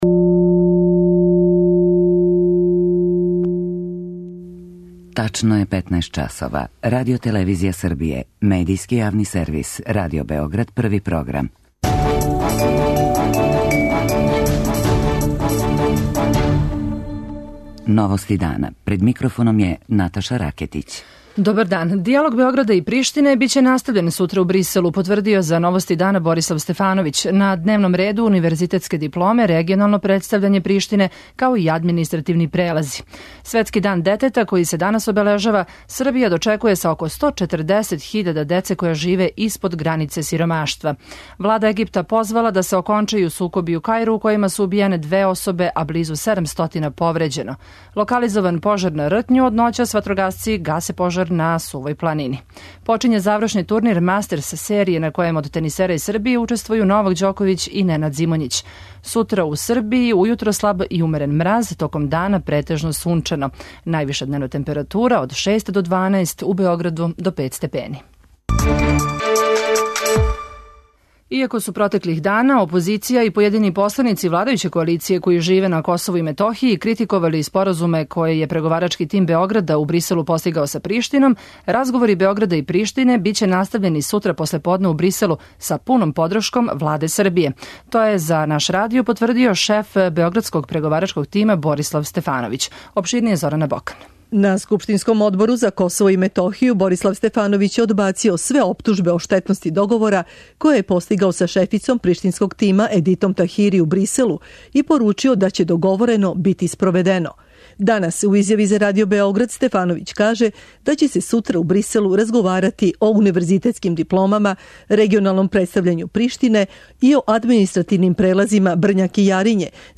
За Новости дана говори помоћник министра за рад и социјалну политику Сузана Пауновић.
преузми : 15.32 MB Новости дана Autor: Радио Београд 1 “Новости дана”, централна информативна емисија Првог програма Радио Београда емитује се од јесени 1958. године.